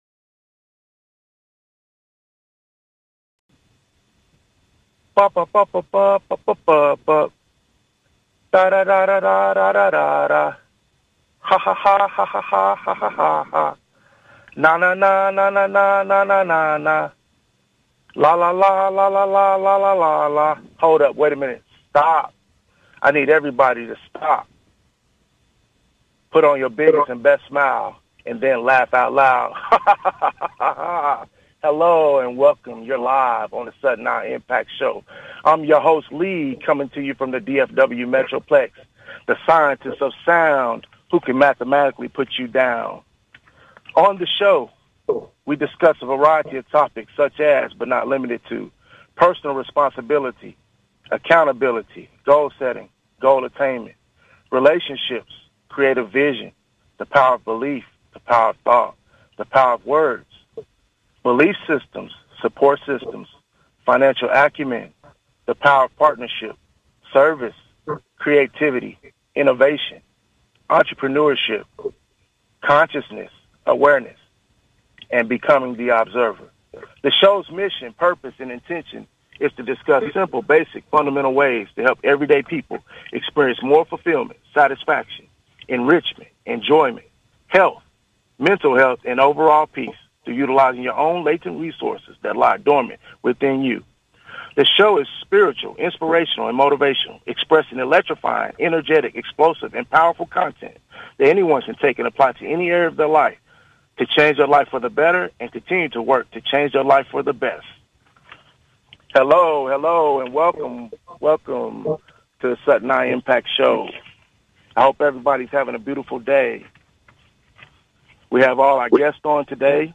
Sudden I Impact (sii) is a talk show about discovery, helping people raise their awareness and identify their gifts, finding things in life they enjoy doing, finding their uniqueness, and potentially turning their passions into businesses that thrive, and most of all, living life by plan and design to earn a living doing what they love (the essential thing).